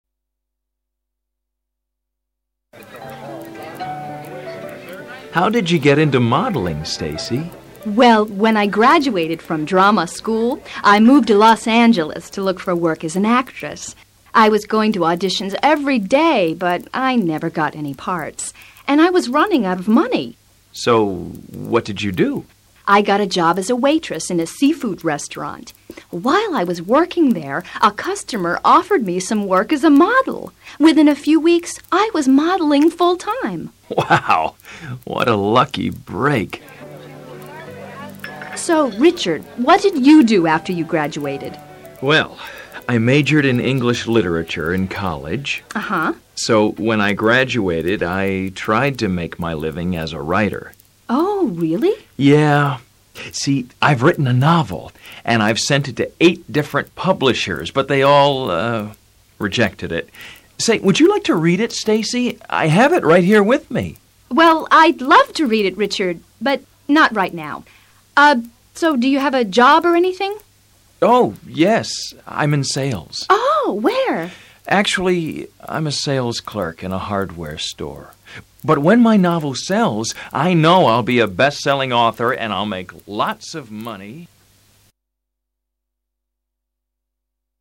Curso de Inglés Nivel Intermedio a Intermedio Alto